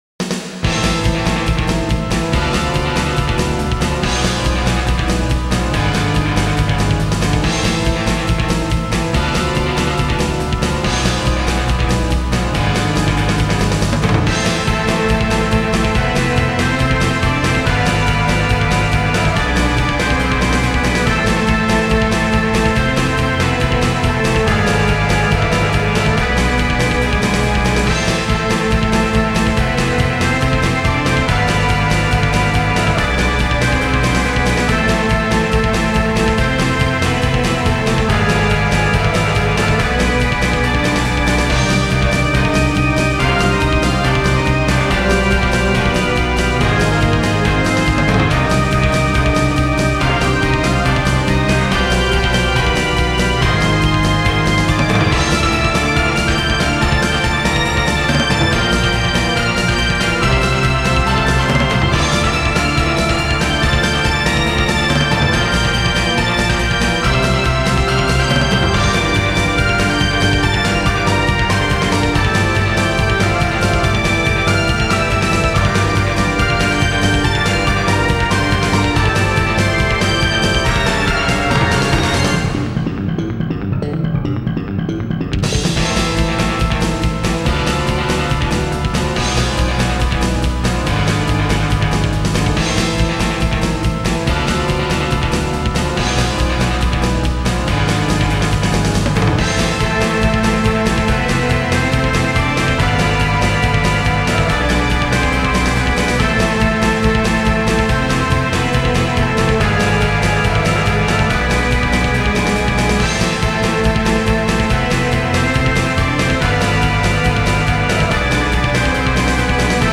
なお、掲載している音源には、EQとコンプレッサー等で若干のマスタリング処理を施してあります。
リズムはメタルロックから借り、ハーモニーはマイナー調をベースに「勇壮でヒロイックなゲーム音楽」をイメージしながら、拙いながらも頑張って紡ぎ出しています。
ミックスは典型的な「風呂場リバーブ」の状態になっていて、その初心者ぶりに我ながら苦笑してしまいますが、当時は、リバーブを使うと音響がリッチになるのが嬉しくて、過剰に使ってしまっていた覚えがあります。
あと、この後もしばらく使い続けることになる「コルグのスネアドラム」が懐かしさを掻き立てます。